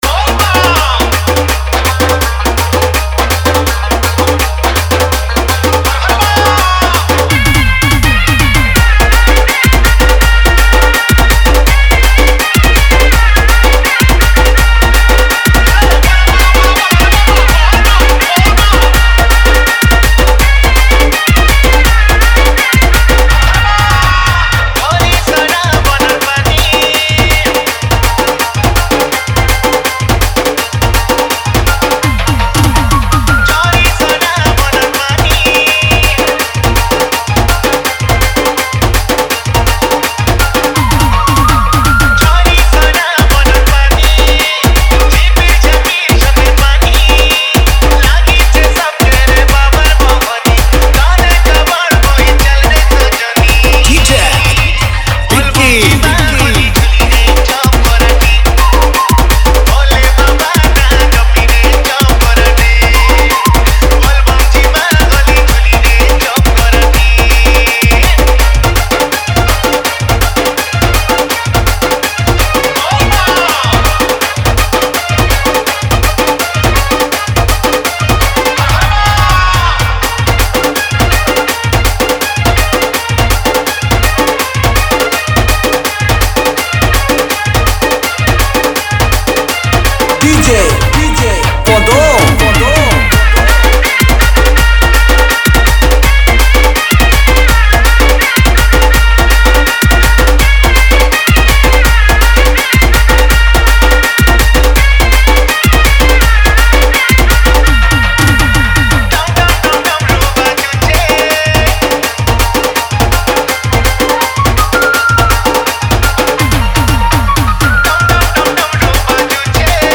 Bolbum Special Dj Song Songs Download